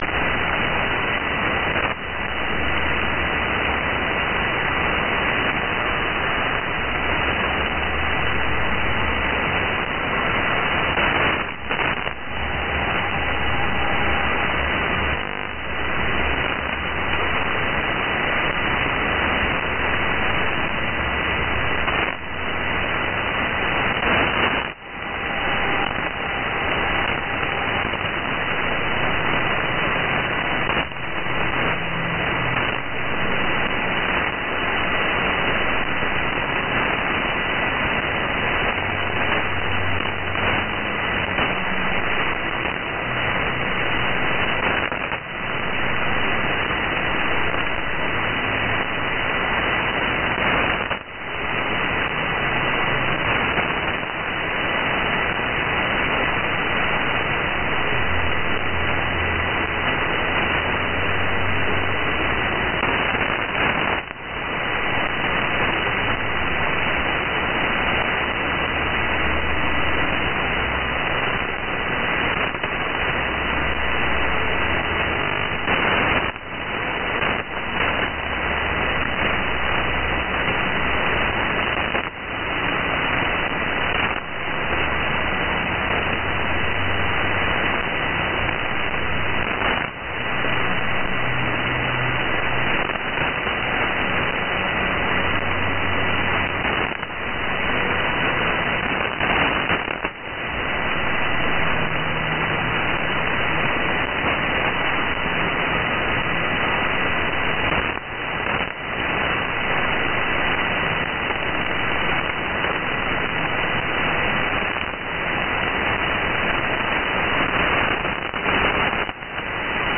SDR recording catch.